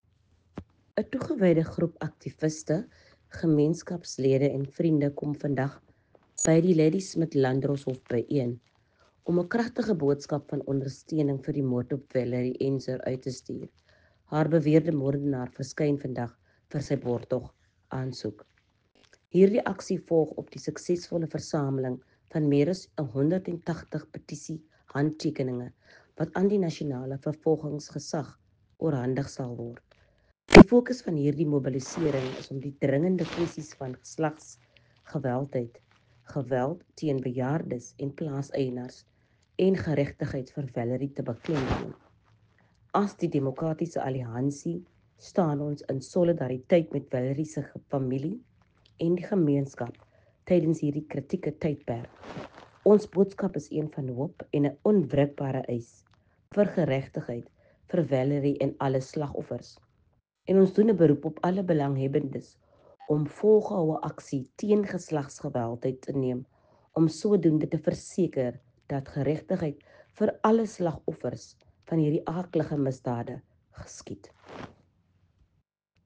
Note to editors: Please find attached soundbites in